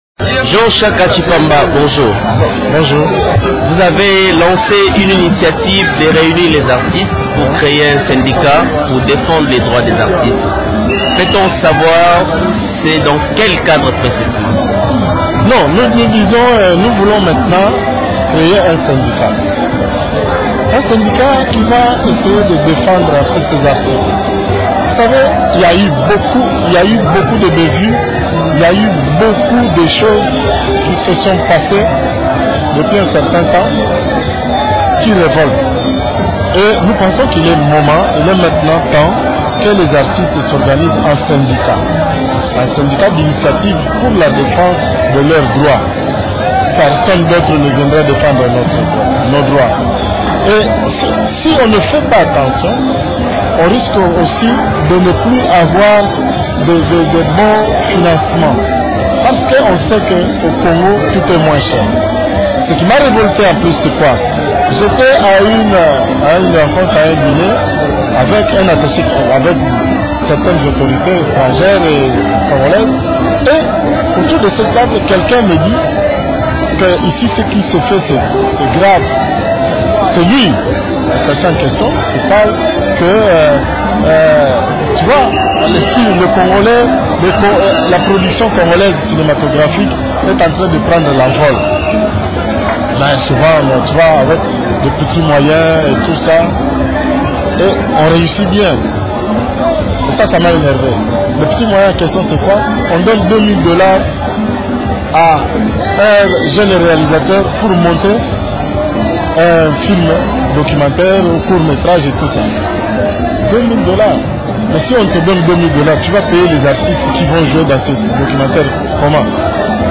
Dans un entretien accordé, vendredi 15 janvier à Radio Okapi, il a indiqué que ce mouvement syndical va les intérêts des artistes congolais de toutes les catégories (musiciens, peintres, sculpteurs, comédiens et autres).